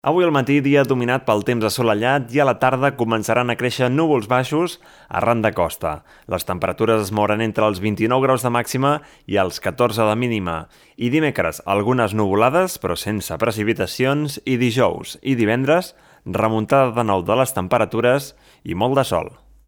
Méteo